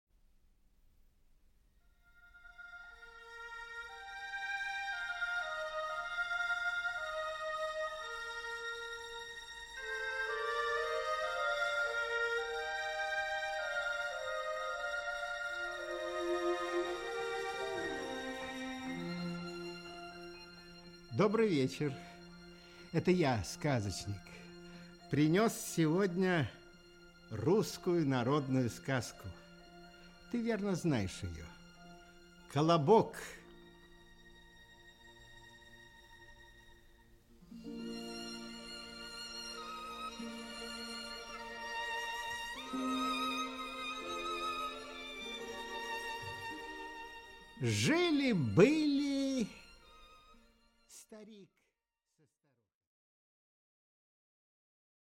Аудиокнига Колобок | Библиотека аудиокниг
Aудиокнига Колобок Автор Народное творчество Читает аудиокнигу Николай Литвинов.